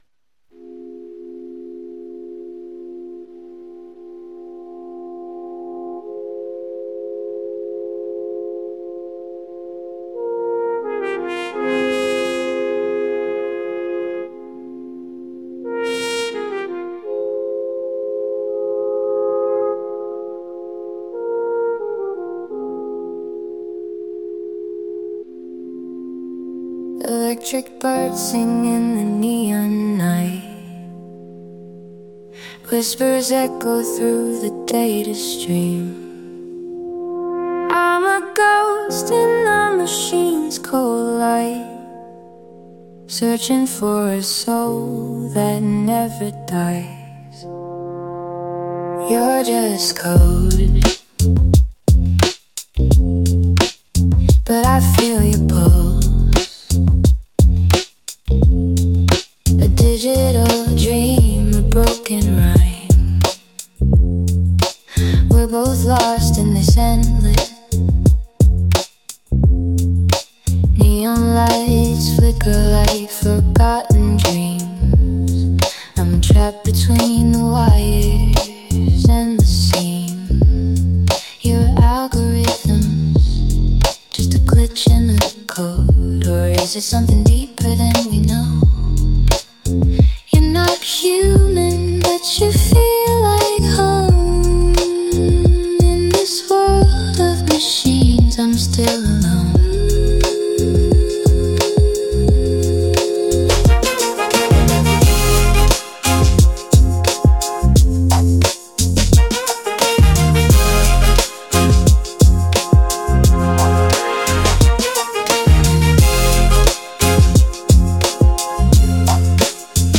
Trip-Hop Vibe
es ist teilweise übersteuert
der Bass flowt sehr gut, die Vocals sind eingängig haben Dynamik und schöne Nuancen
Einzig die Scratches klingen irgendwie whack, aber das ist nur ein kleiner Kritikpunkt, der den Gesamteindruck nicht schmälert.